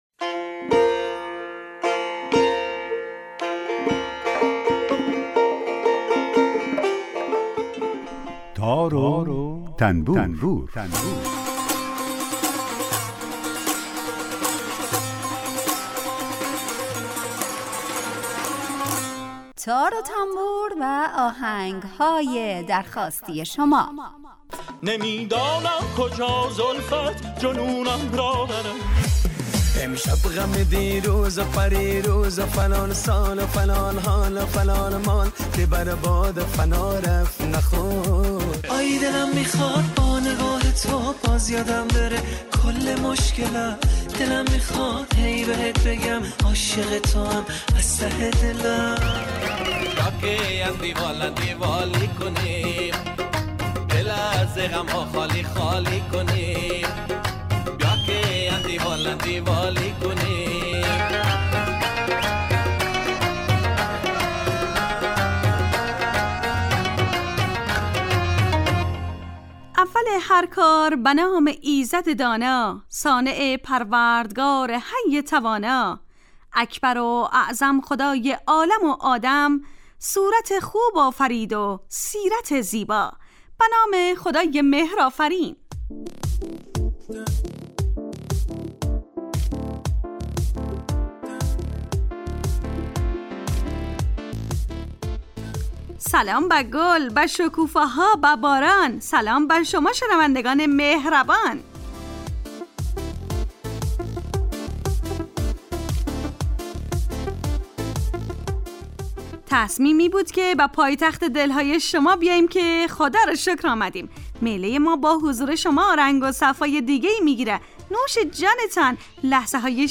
آهنگ های درخواستی